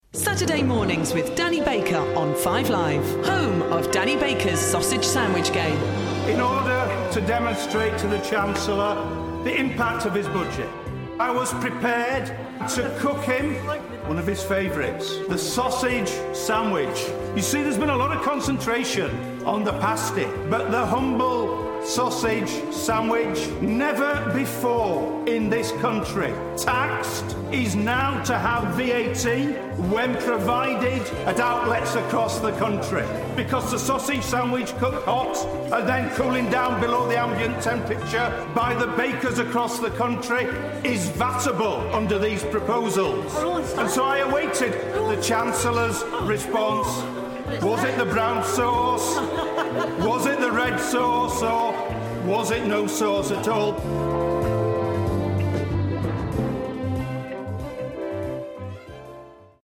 Clip from the Finance Bill debate on taxing the humble sausage sandwich - used for Danny Baker's Sausage Sandwich Game.